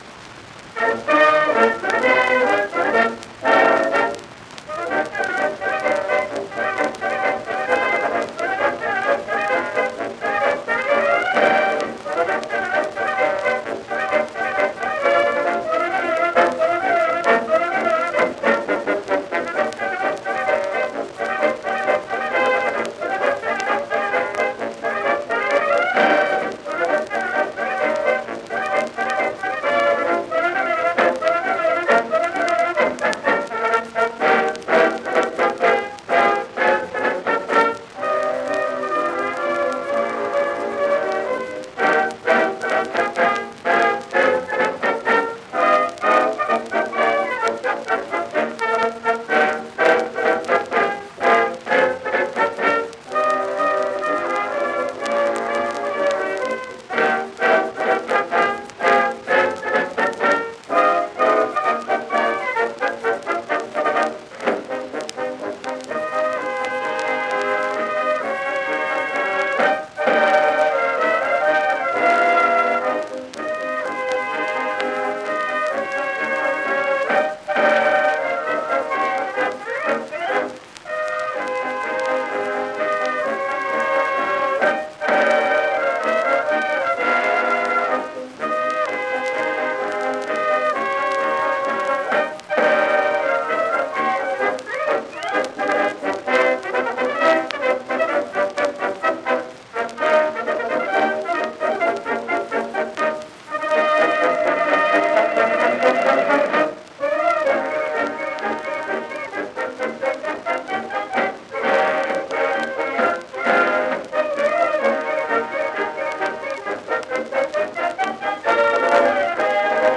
The U.S. Marine Band play "True to the Flag March" in 1922